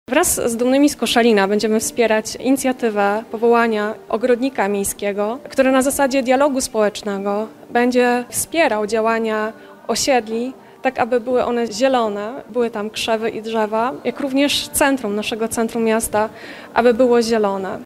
Wizje, plany i zamierzenia zostały przedstawione na poniedziałkowej konferencji prasowej.